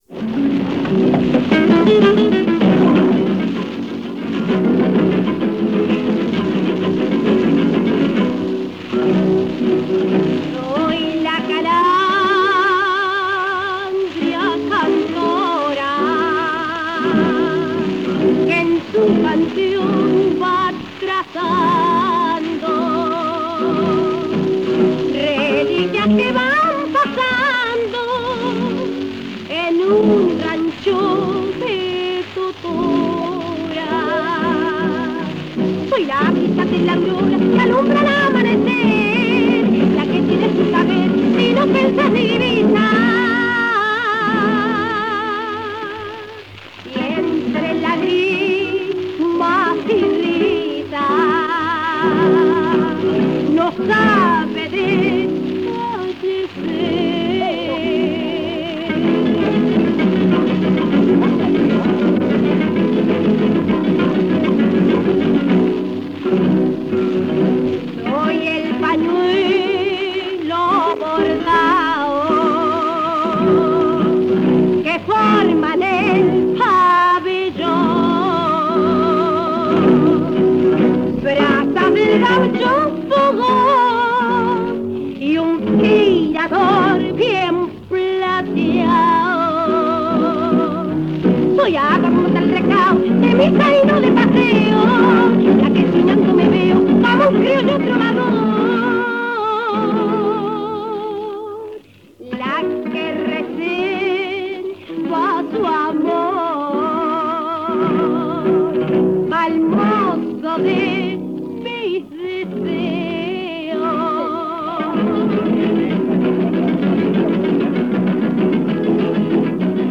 Ejecutante, edad, instrumental: Conjunto tradicional «La estancia», guitarras y voz (sin dato de nombre de las personas que interpretan)
Localidad: Montevideo (en el Teatro de Verano del Parque Rodó), departamento de Montevideo, Uruguay
Formato original de la grabación: disco de acetato Duodisc de 25 cm de base metálica a 78 rpm
Comentarios: Grabado durante el Concurso Municipal de Agrupaciones Carnavalescas de 1946.